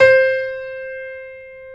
C4-PNO93L -L.wav